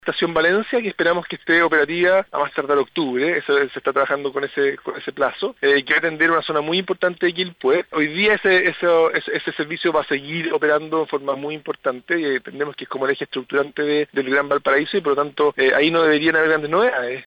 La Estación Valencia será una realidad en los próximos meses del 2025, esto, según las declaraciones del ministro de Transporte y Telecomunicaciones, Juan Carlos Muñoz, en conversación con La Radio en Valparaíso.